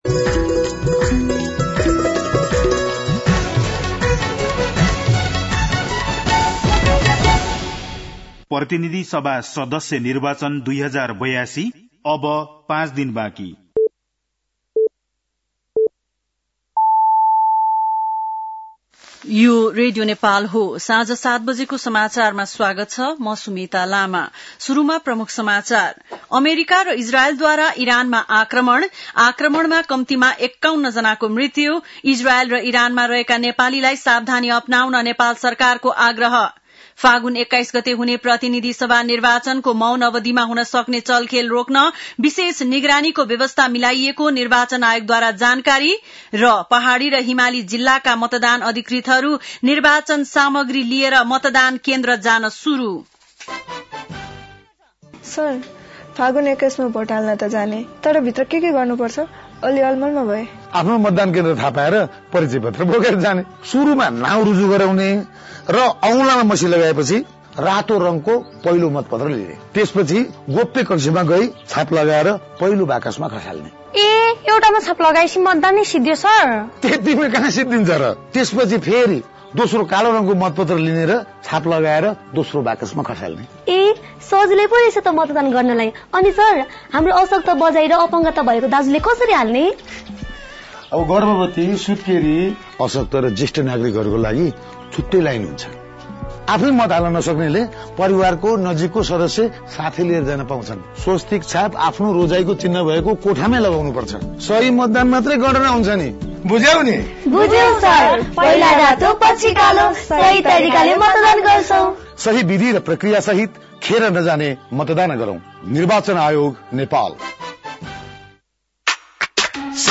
बेलुकी ७ बजेको नेपाली समाचार : १६ फागुन , २०८२